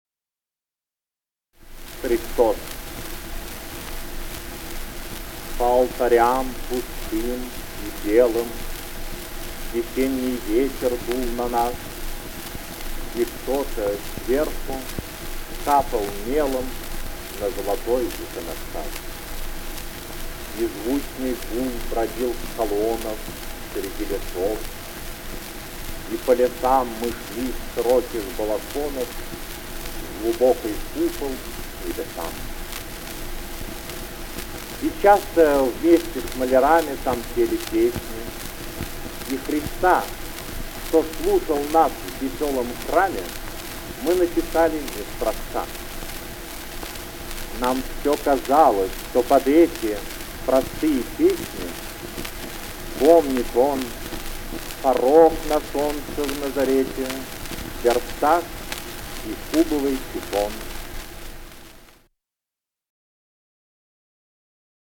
(Голоса авторов) Иван Бунин